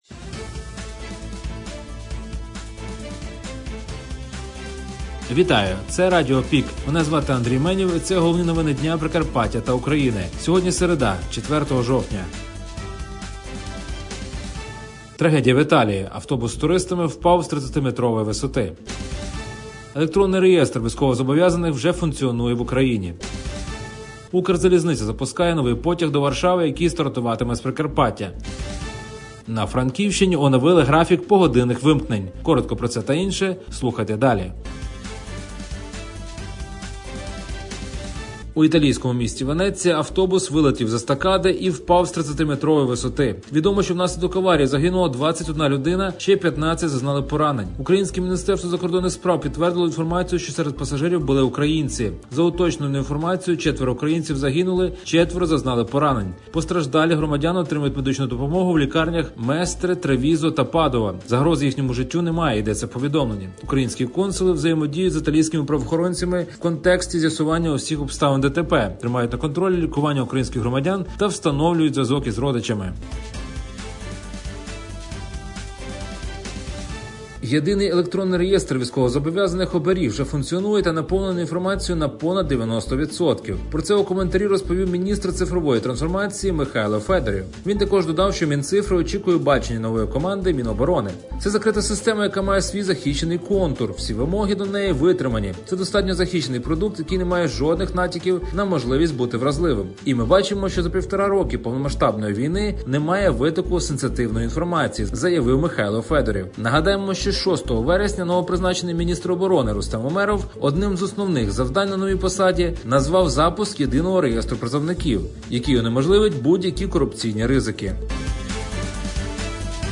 Радіо ПІК: головні новини Прикарпаття та України за 4-го жовтня (ПРОСЛУХАТИ)